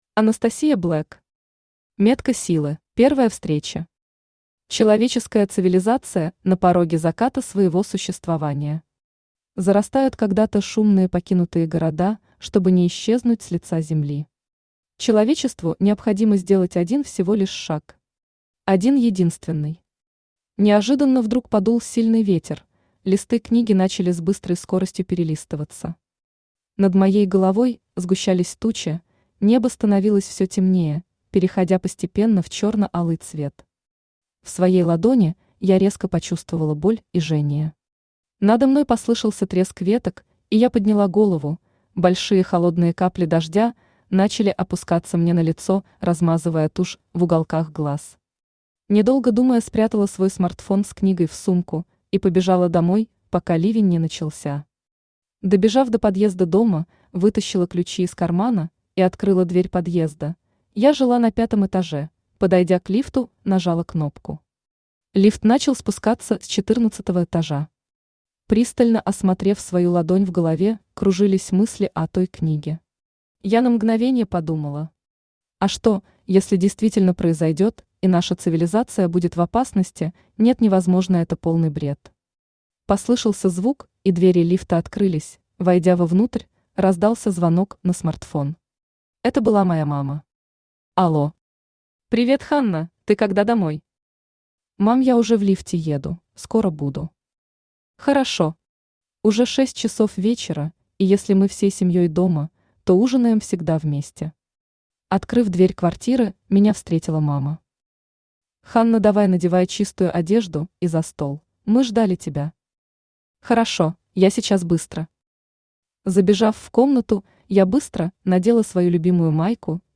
Aудиокнига Метка силы Автор Анастасия Блэк Читает аудиокнигу Авточтец ЛитРес.